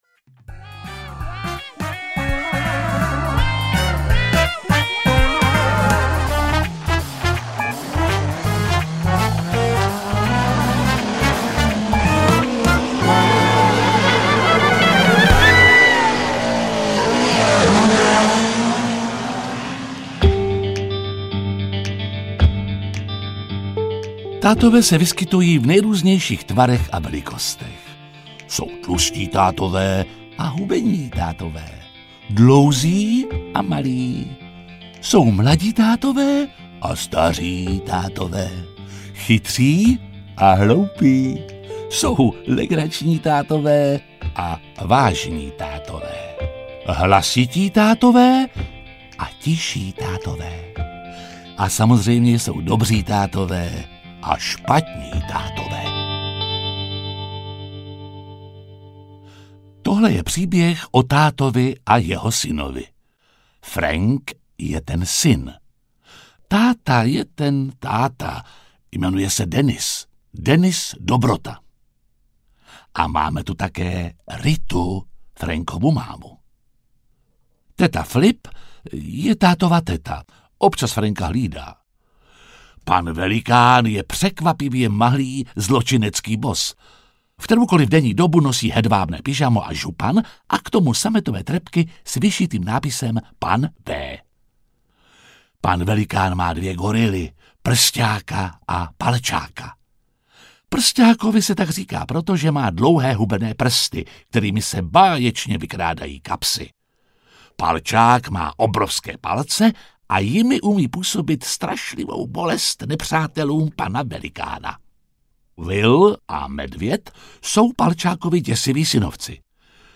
Táta za všechny prachy audiokniha
Ukázka z knihy
• InterpretJiří Lábus